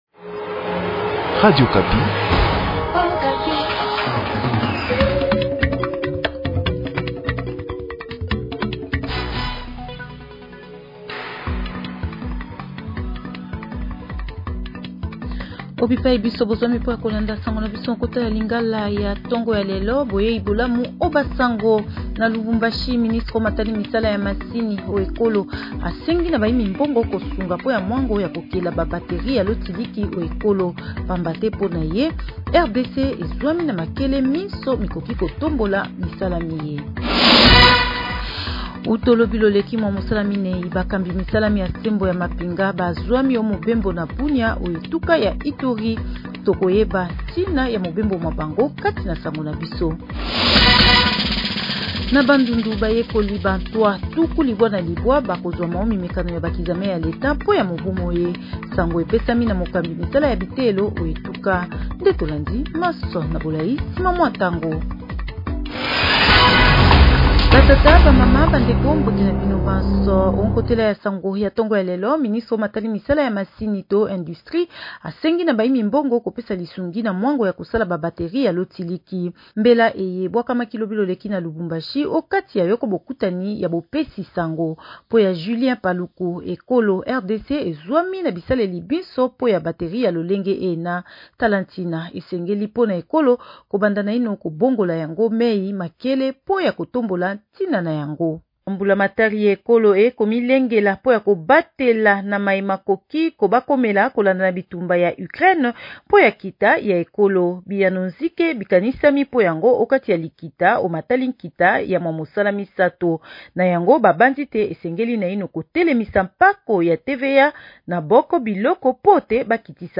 Journal Lingala Matin